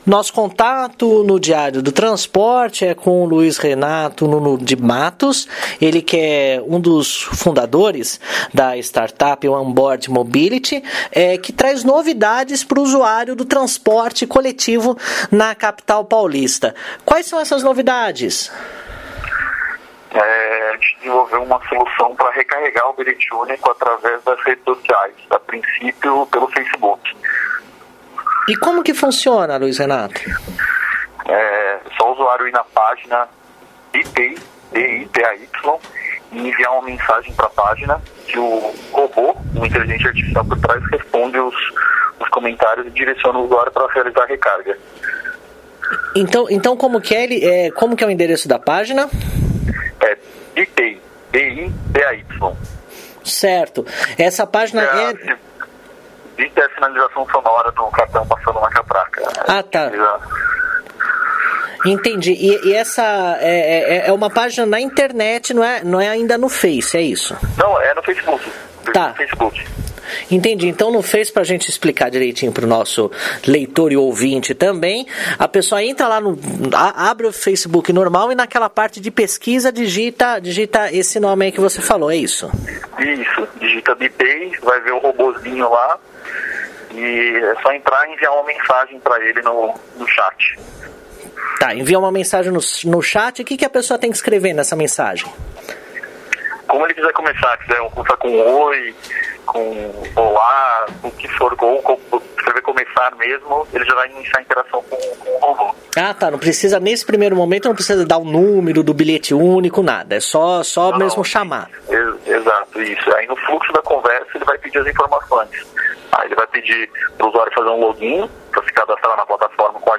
Entrevista-Facebook-Bilhete-Único-1.mp3